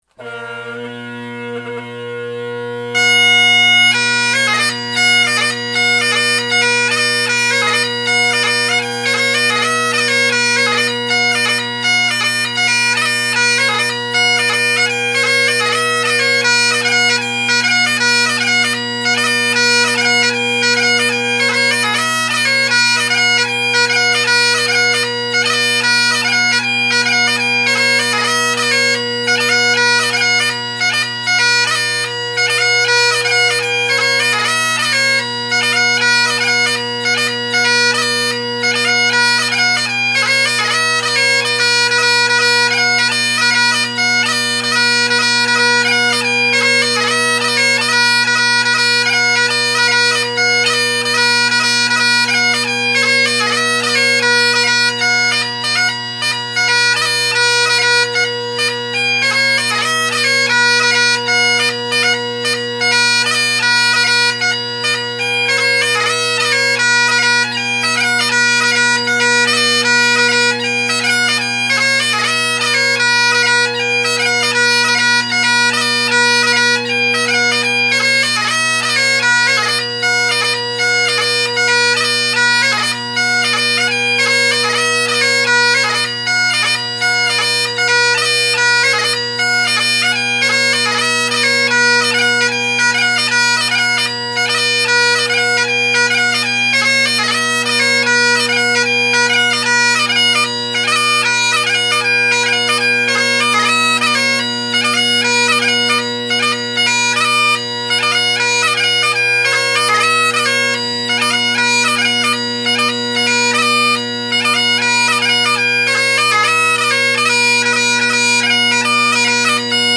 Below are several recordings made over several days of most of my collection of bagpipes.
Jacky Latin (Glencoe drones, Canning reeds with carbon fiber bass, Colin Kyo chanter + Husk reed) – mic off to the left (recorded 2019-08-19)